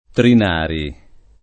[ trin # ri ]